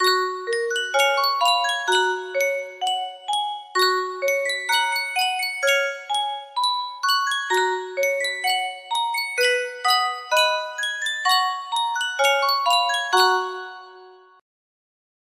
Sankyo Music Box - Careless Love YSS music box melody
Full range 60